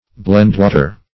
Search Result for " blendwater" : The Collaborative International Dictionary of English v.0.48: Blendwater \Blend"wa`ter\, n. A distemper incident to cattle, in which their livers are affected.